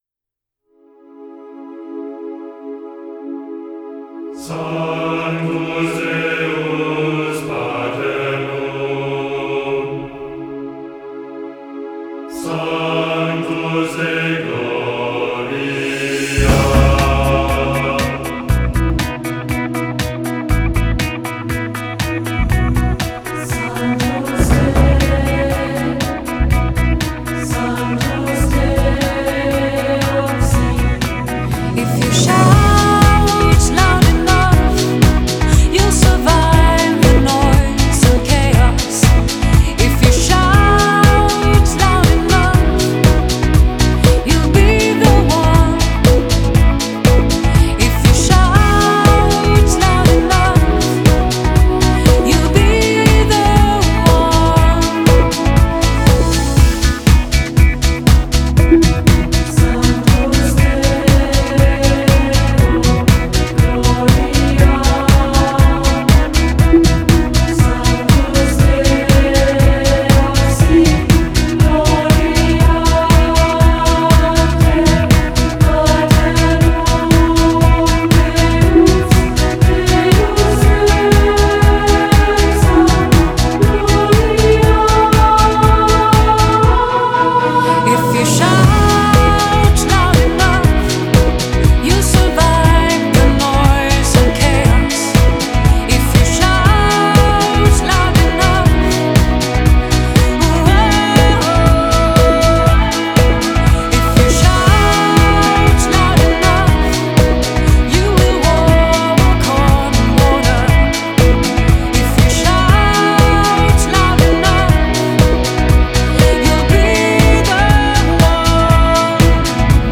Genre : Ambient, Enigmatic, New Age